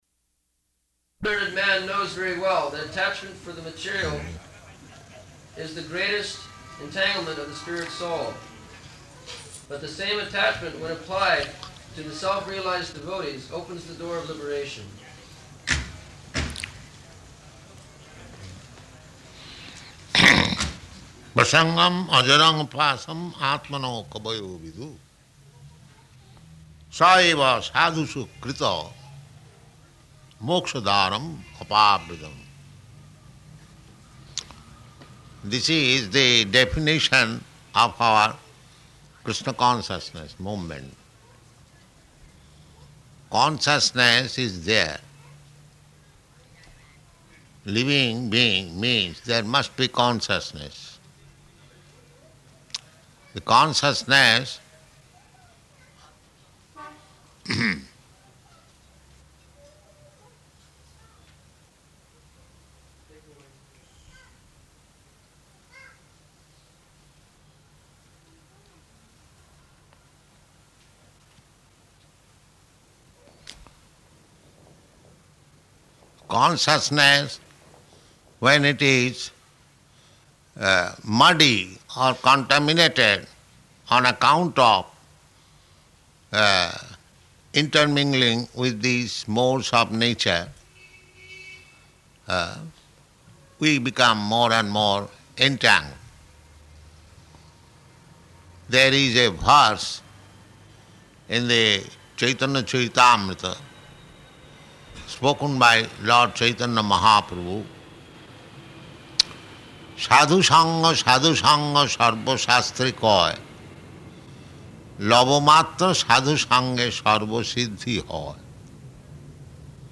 Location: Bombay
[sound of child in background] [pause] Devotee: Take away the girl.
[pause] [sound of child continuing] No...